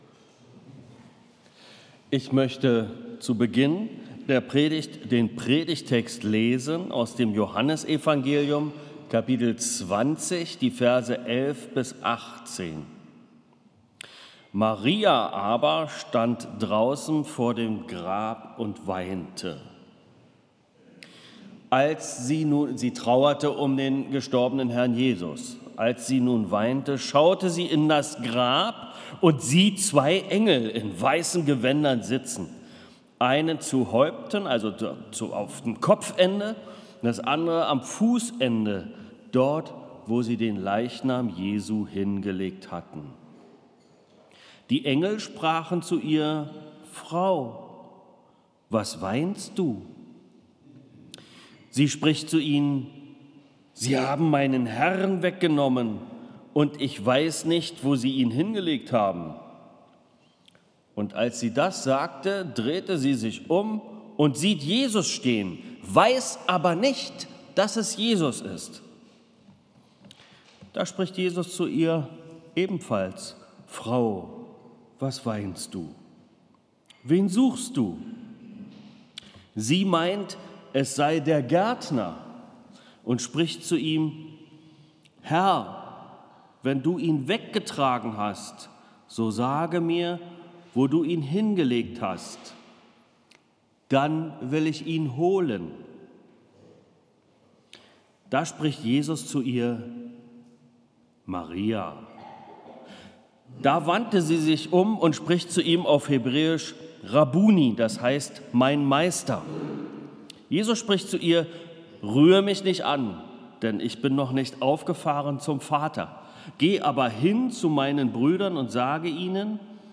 Predigt | Bethel-Gemeinde Berlin Friedrichshain